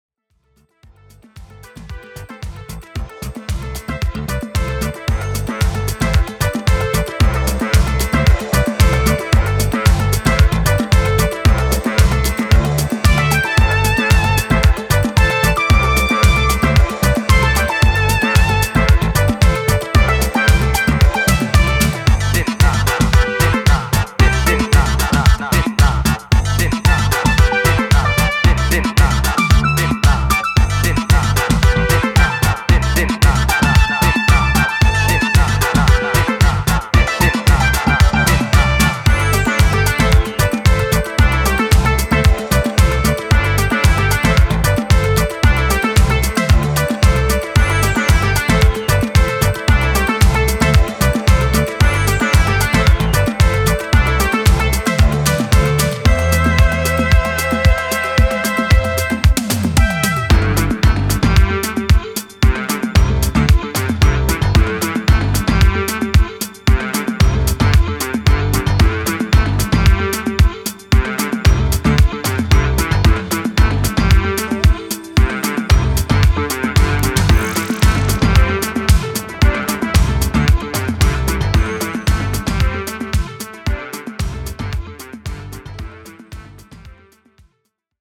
楽観的なメロディーが光る地中海性ブギー